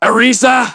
synthetic-wakewords
ovos-tts-plugin-deepponies_Trevor_en.wav